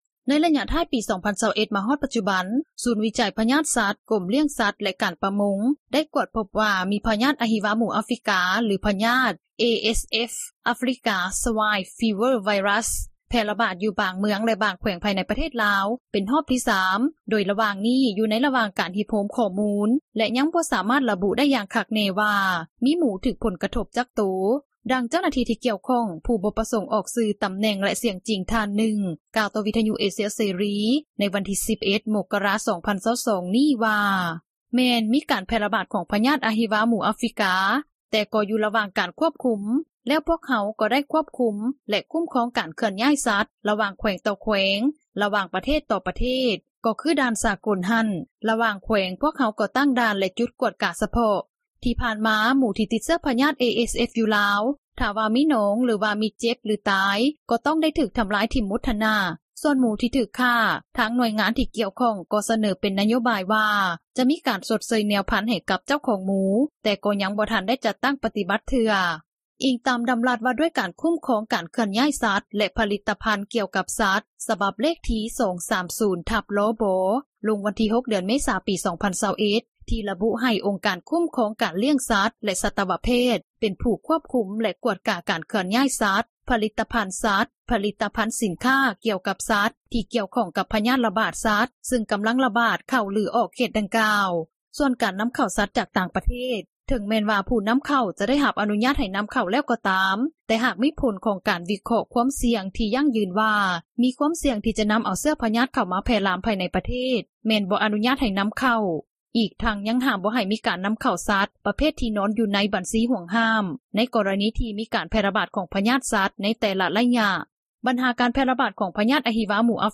ດັ່ງເຈົ້າໜ້າທີ່ ທີ່ກ່ຽວຂ້ອງ ຜູ້ບໍ່ປະສົງອອກຊື່ ຕໍາແໜ່ງ ແລະສຽງຈິງ ທ່ານນຶ່ງ ກ່າວຕໍ່ວິທຍຸເອເຊັຽເສຣີ ໃນວັນທີ່ 11 ມົກກະຣາ 2022 ນີ້ວ່າ:
ດັ່ງຜູ້ປະກອບການ ດ້ານການຄ້າໝູ ທ່ານນຶ່ງ ທີ່ບໍ່ປະສົງອອກສຽງຈິງ ກ່າວໃນມື້ດຽວກັນນີ້ວ່າ: